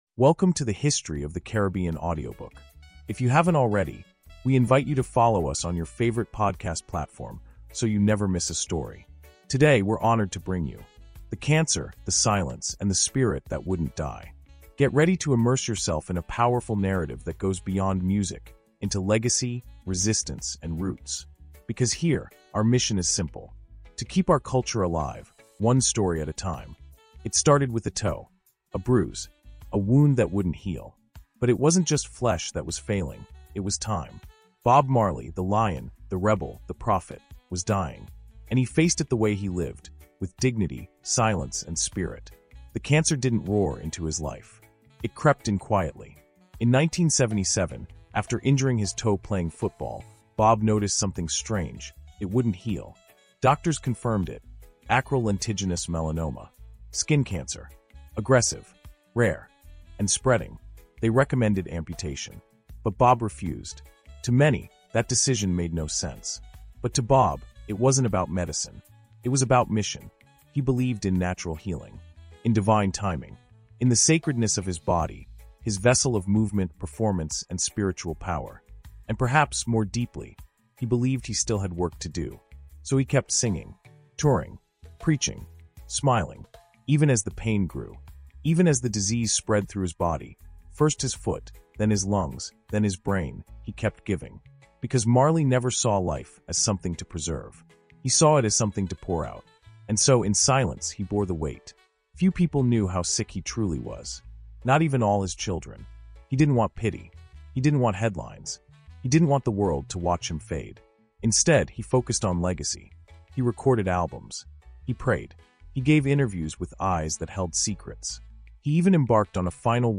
The Cancer, the Silence, and the Spirit That Wouldn’t Die” is a powerful and intimate audio reflection on the final chapter of Bob Marley’s life. This insight tells the story of how the reggae legend faced terminal illness—not with fear, but with grace, dignity, and deep spiritual purpose.